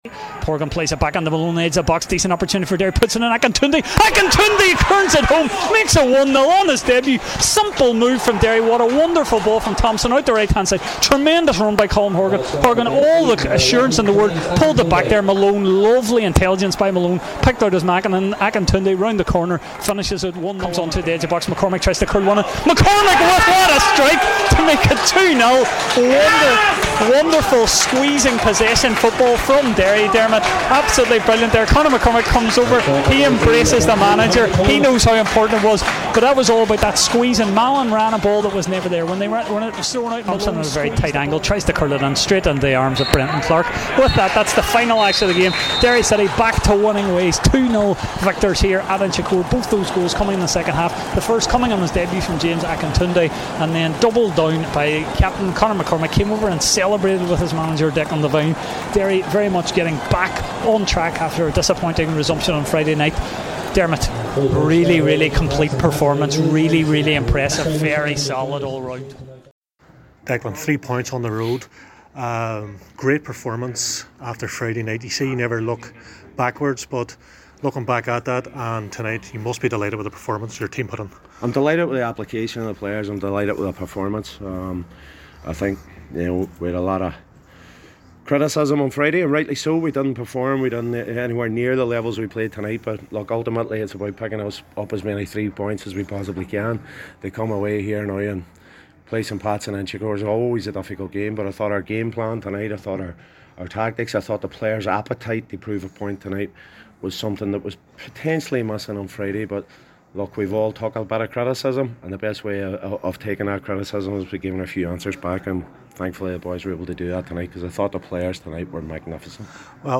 St. Patrick's Athletic 0-2 Derry City, goal highlights plus post match interview